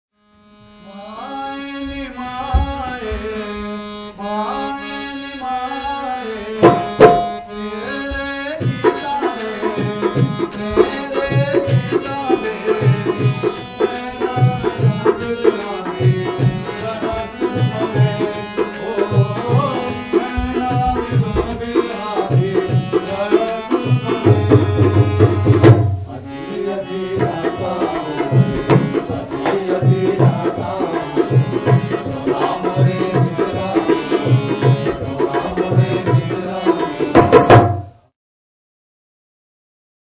The mood is exuberant, the singing and dancing adds to it.